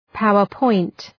Προφορά
power-point.mp3